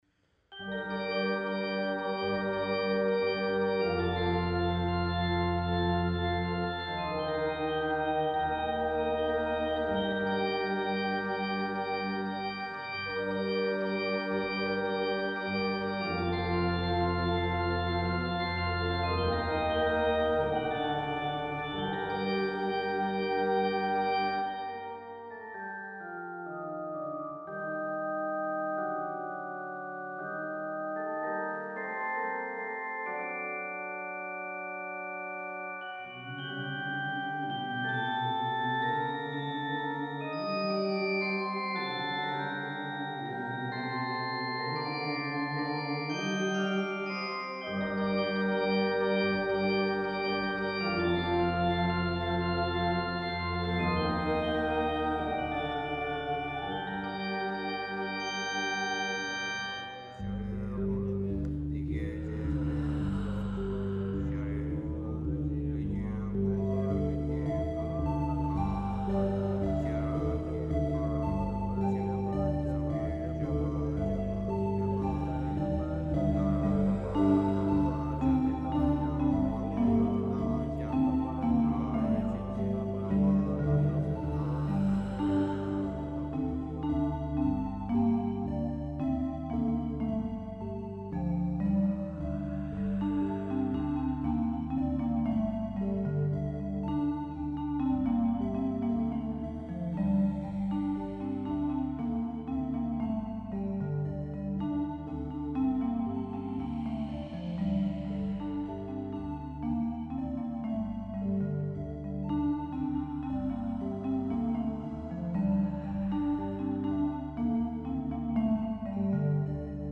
Keyboards
Voice